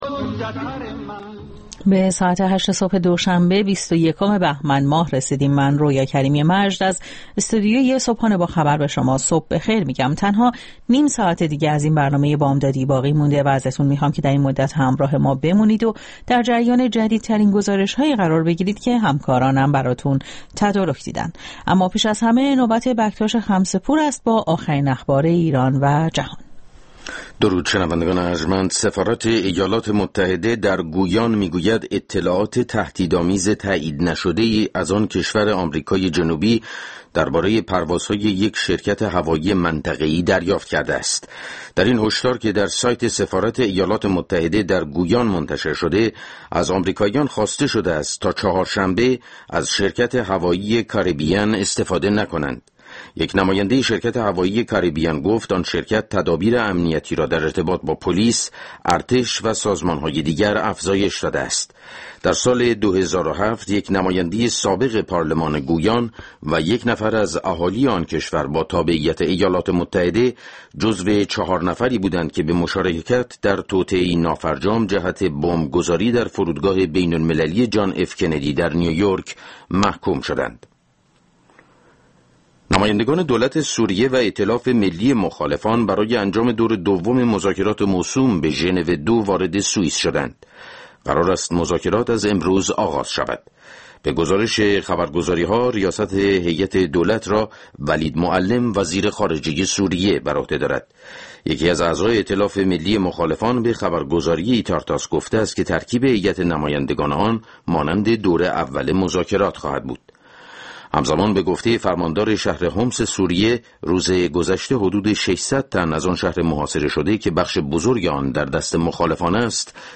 گزارشگران راديو فردا از سراسر جهان، با تازه‌ترين خبرها و گزارش‌ها، مجله‌ای رنگارنگ را برای شما تدارک می‌بينند. با مجله بامدادی راديو فردا، شما در آغاز روز خود، از آخرين رويدادها آگاه می‌شويد.